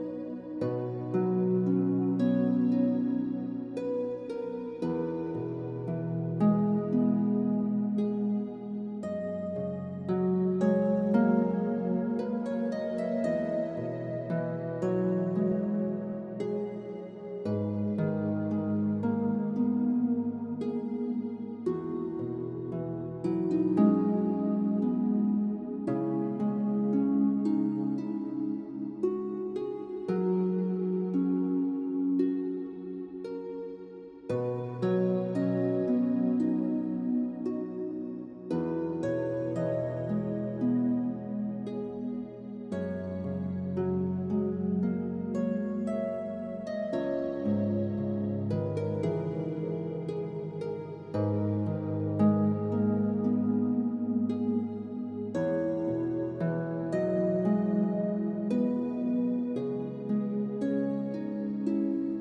Enjoy a 1-Minute Sample – Purchase to Hear the Whole Track